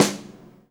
snare 4.wav